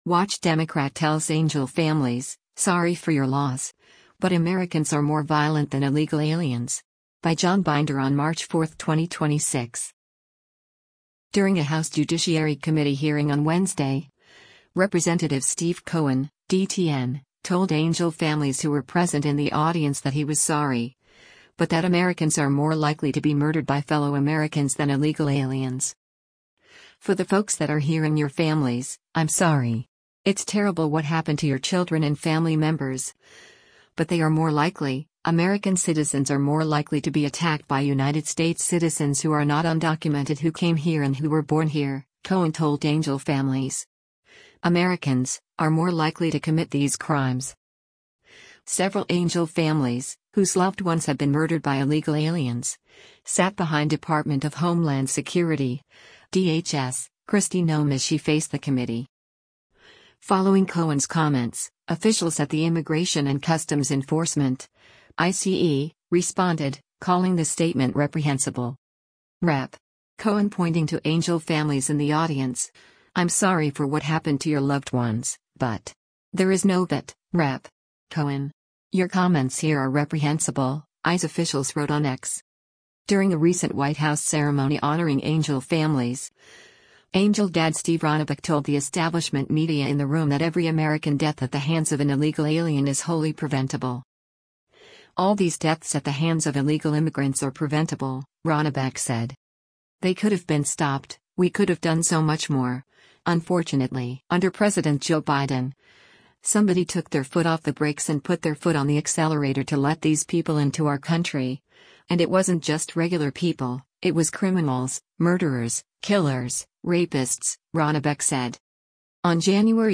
During a House Judiciary Committee hearing on Wednesday, Rep. Steve Cohen (D-TN) told Angel Families who were present in the audience that he was “sorry,” but that Americans are more likely to be murdered by fellow Americans than illegal aliens.